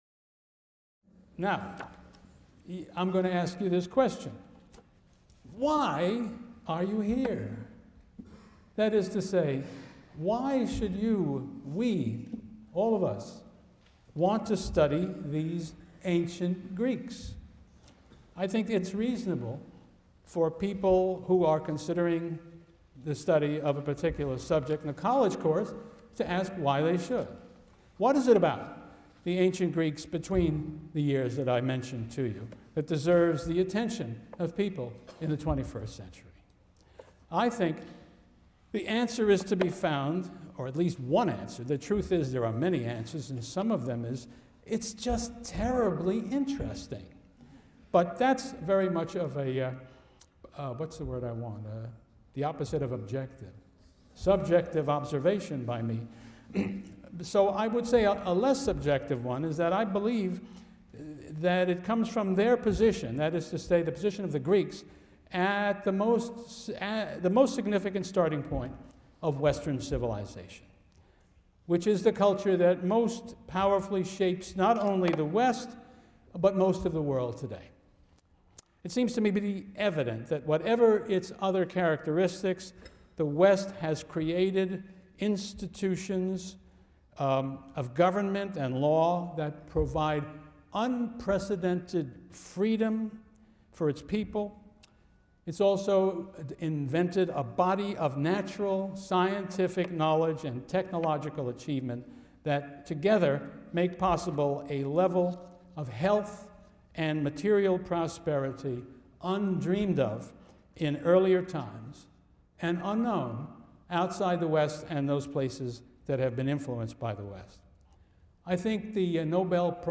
CLCV 205 - Lecture 1 - Introduction | Open Yale Courses